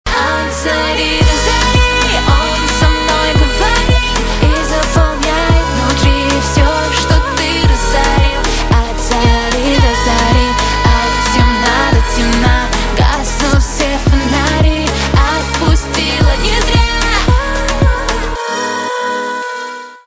Рингтон